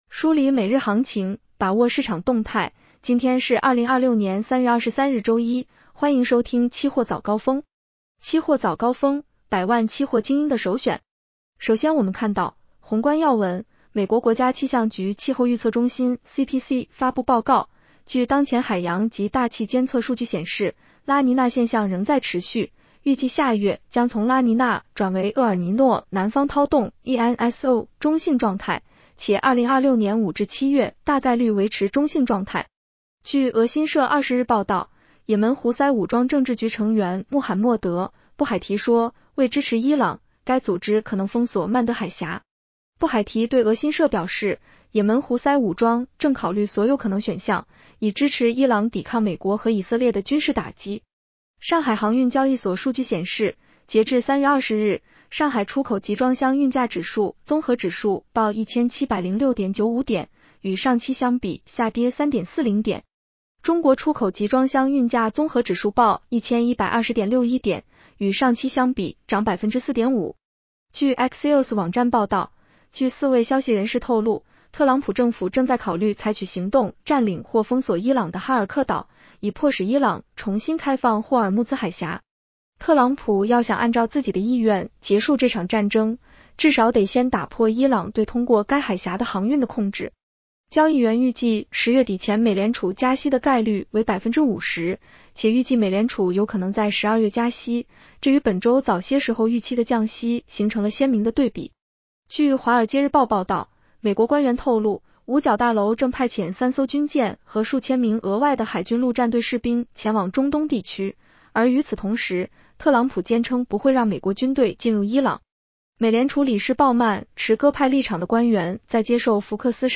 期货早高峰-音频版 女声普通话版 下载mp3 热点导读 1.交易员预计10月底前美联储加息的概率为50%，且预计美联储有可能在12月加息，这与本周早些时候预期的降息形成了鲜明的对比。